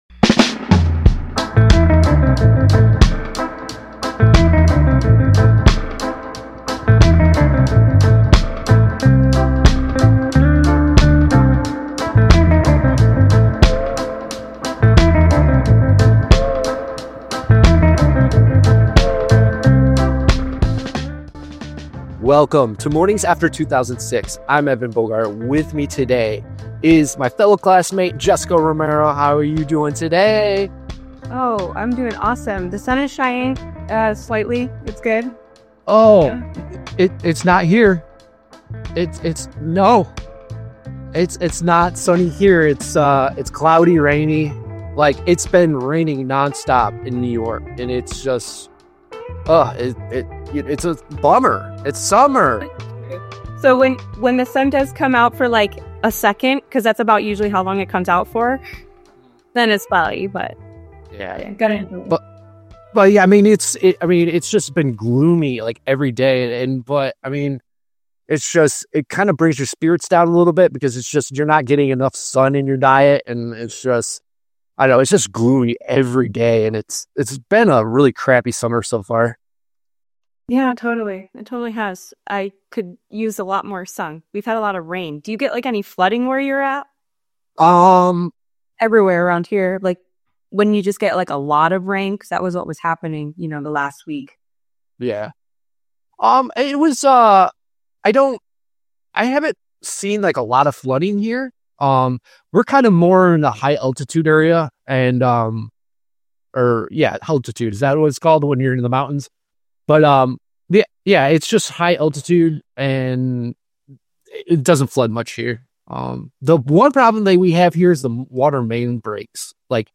Morning After 2006 is a laid-back podcast where two former classmates catch up and speak their minds about everything from current events to personal experiences.